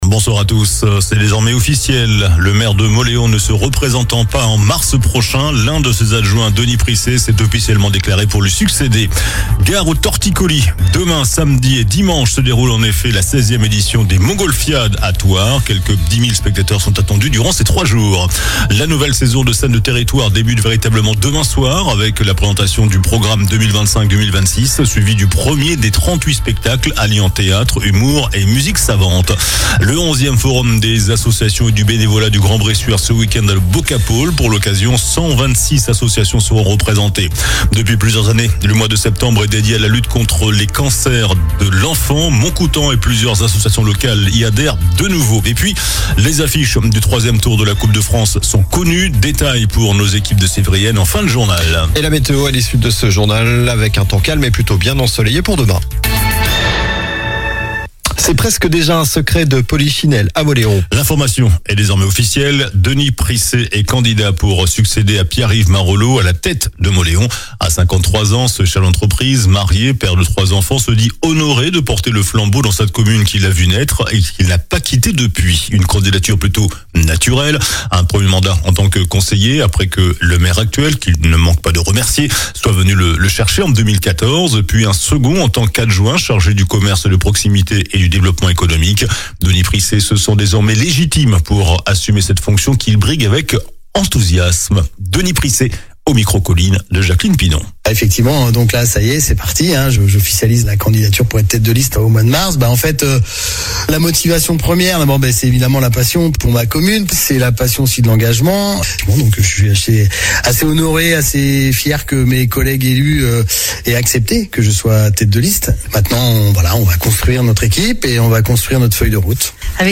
JOURNAL DU JEUDI 04 SEPTEMBRE ( SOIR )